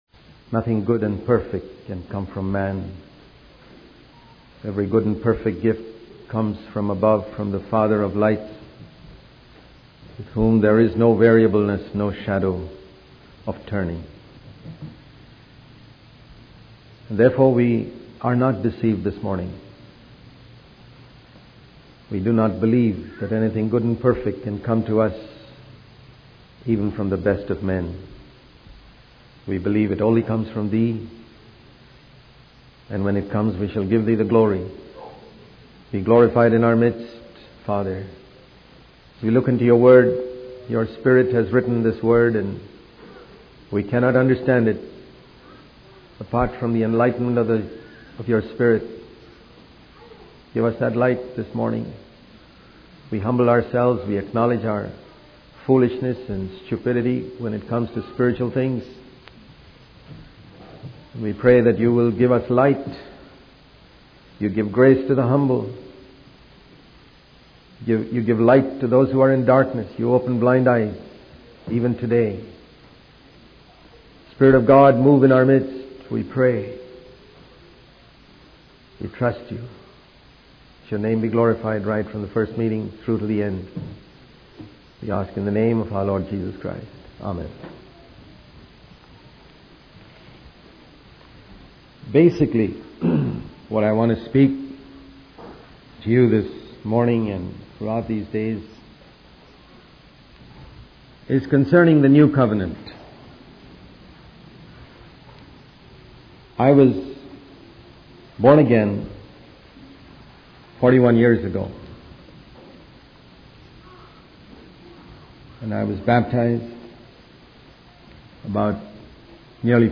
In this sermon, the preacher emphasizes the importance of repentance in the Christian faith. He highlights the need for believers to turn away from idols and fully devote themselves to God.